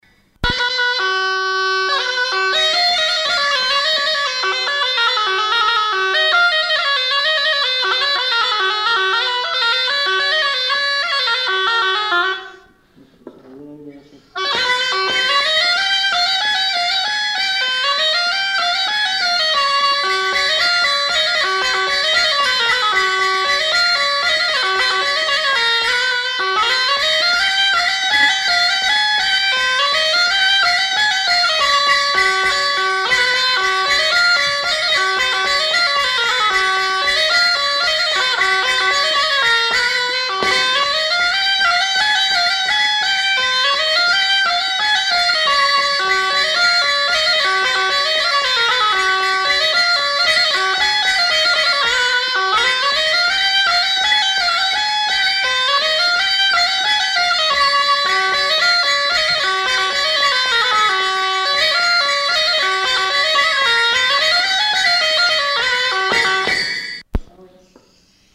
Lieu : Marvejols
Genre : morceau instrumental
Instrument de musique : cabrette ; grelot
Danse : scottish-valse